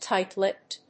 アクセントtíght‐lípped
音節tìght-lípped発音記号・読み方-lɪ́pt